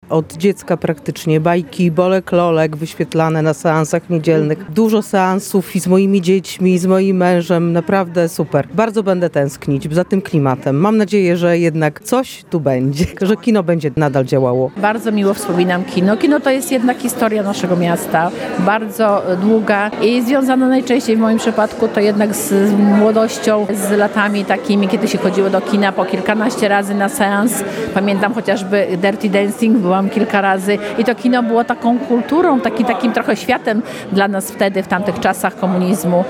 – To był jeden z najważniejszych ośrodków kulturalnych w mieście, z którym wiążą się najpiękniejsze wspomnienia – mówią Bialczanie.